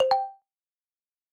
alert.mp3